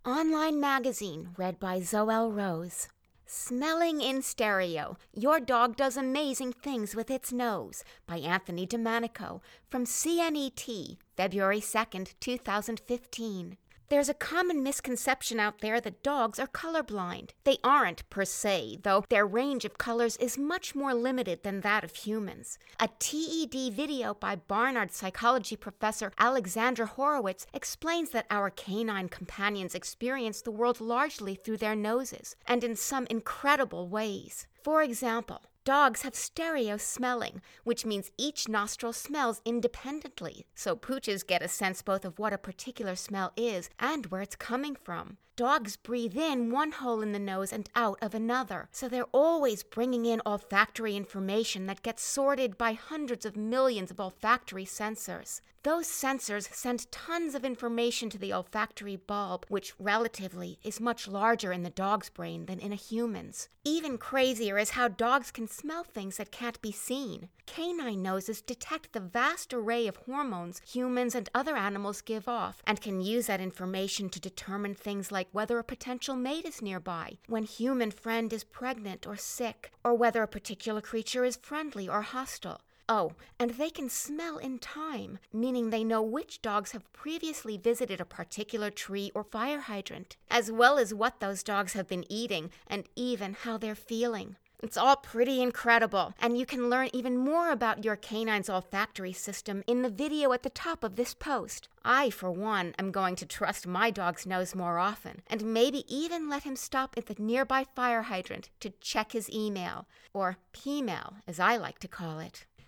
Soft-spoken, genuine, animated, intelligent, confident, soothing, caring, conversational.
Sprechprobe: eLearning (Muttersprache):